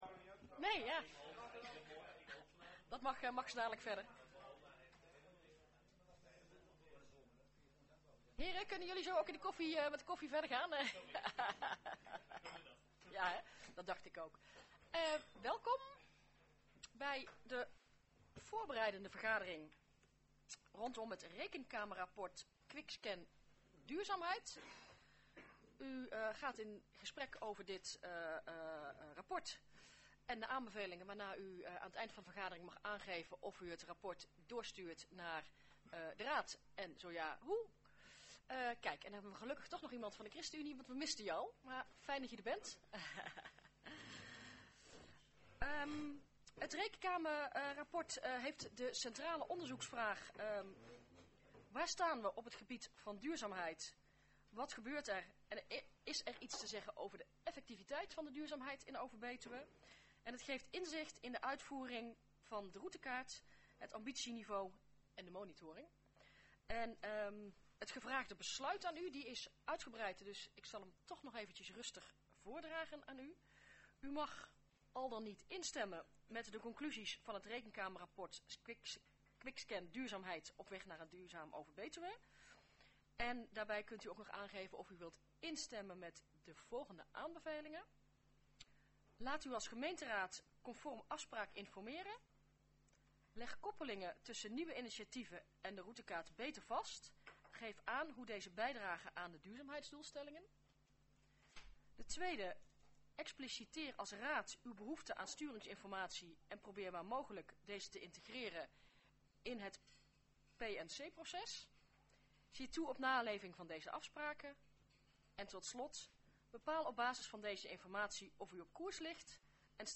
Locatie De Oldenburg, Driel Voorzitter mevr. J. Rouwenhorst Toelichting Voorbereidende vergadering Rekenkamerrapport Quick scan duurzaamheid Agenda documenten 20-01-21 Opname 2.